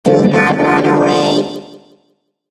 evil_rick_start_vo_04.ogg